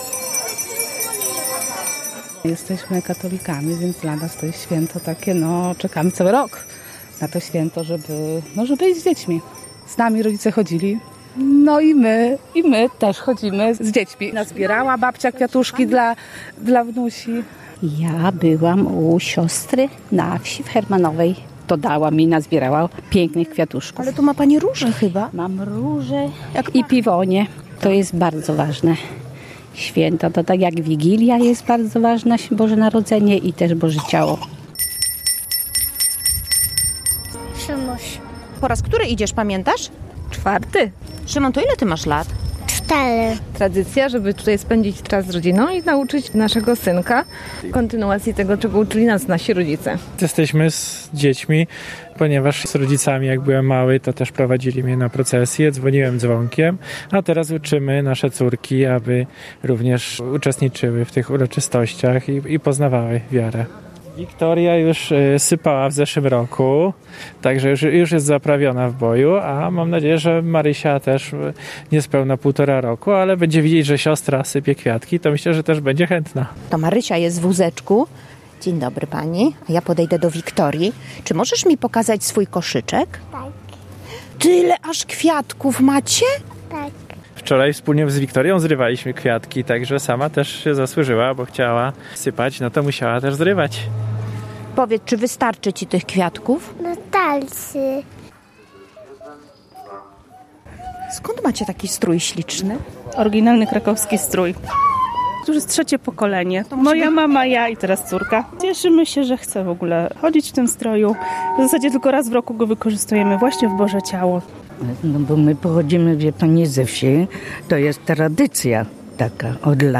Procesja Bożego Ciała w Rzeszowie (zdjęcia, relacja)